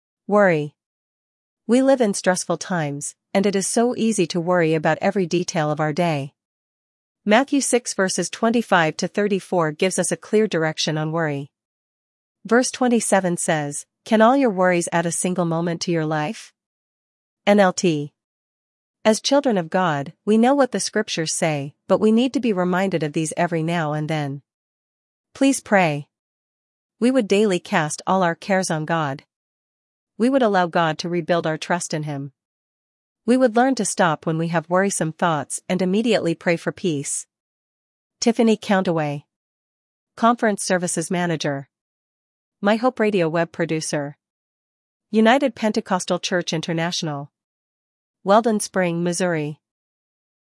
Access and listen to the AI Audio Information or see the Text/Word Information for your convenience and attention.